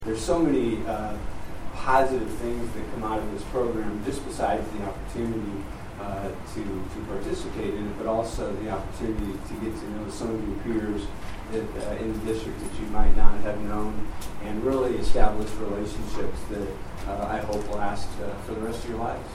Mayor Jim Ardis says the program also builds strong new relationships.